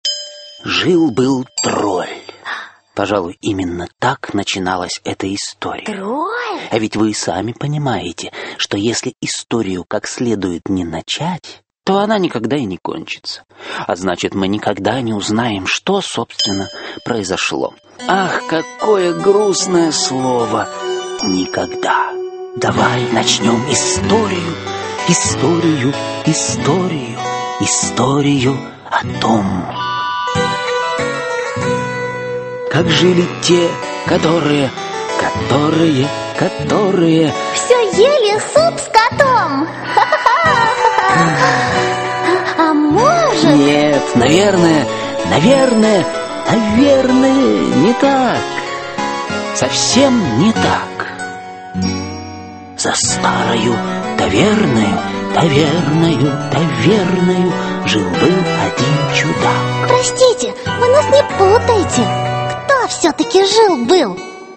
Аудиокнига Снежная королева (спектакль) | Библиотека аудиокниг